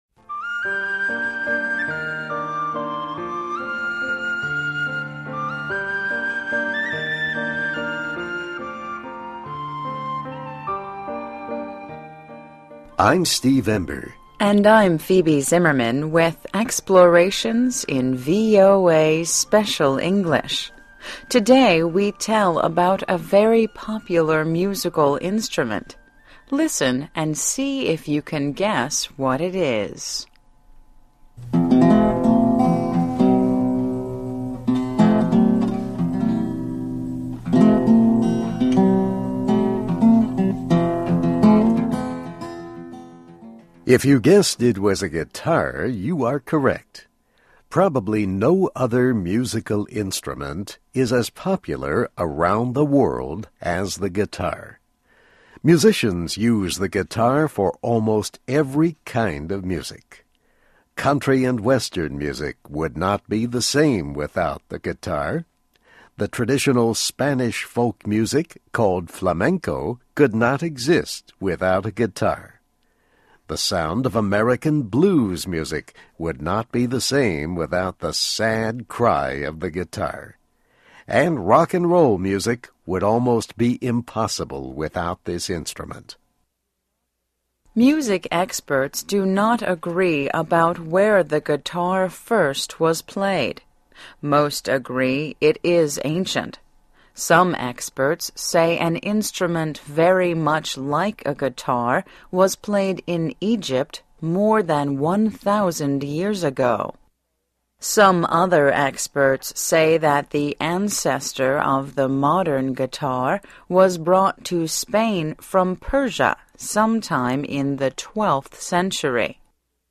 Listen as he plays a Flamenco song called "Jerez."
se-exp-guitar-28mar12.mp3